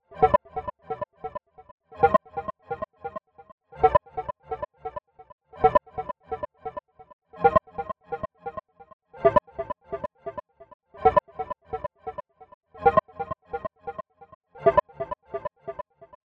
• lead techno psychodelic echo - Cm.wav
lead_techno_psychodelic_echo_-_Cm_7L4.wav